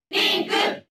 File:Toon Link Cheer Japanese SSBB.ogg
File usage The following 3 pages use this file: List of crowd cheers (SSBB)/Japanese Toon Link (SSBB) File:Toon Link Cheer JP Brawl.ogg Transcode status Update transcode status No transcoding required.
Toon_Link_Cheer_Japanese_SSBB.ogg